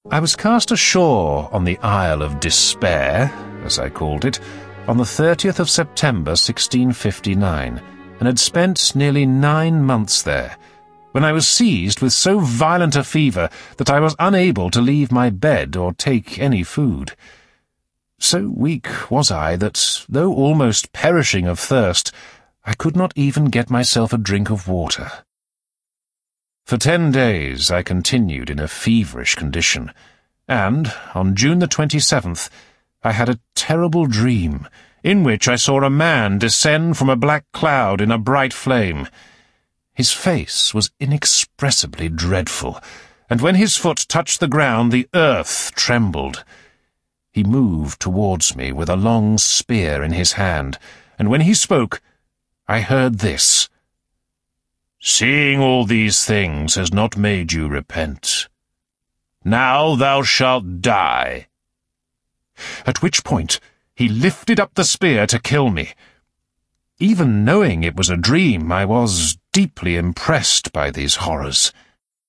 Robinson Crusoe (EN) audiokniha
Ukázka z knihy